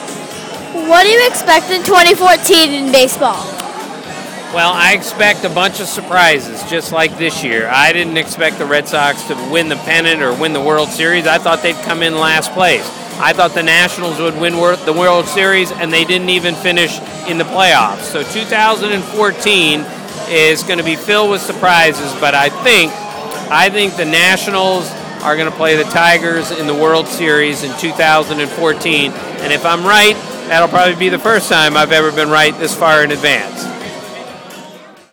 That’s all I have for now of my interview of Tim Kurkjian.